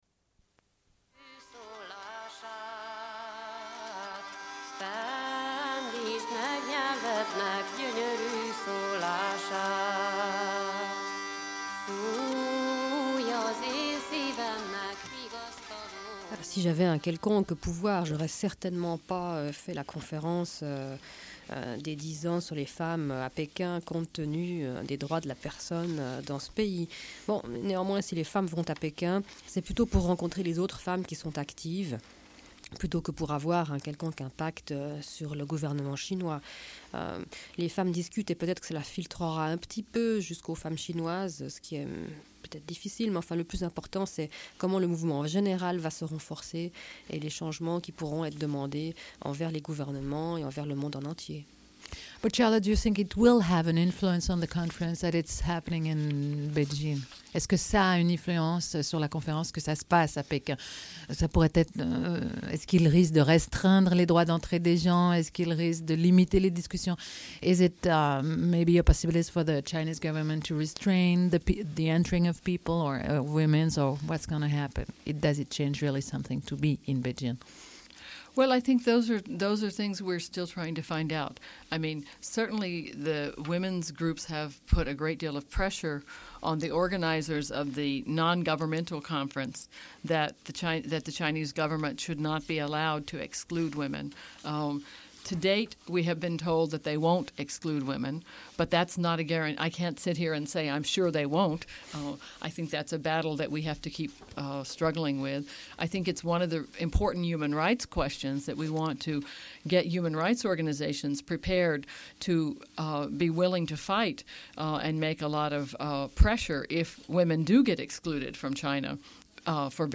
Une cassette audio, face A30:54
Radio Enregistrement sonore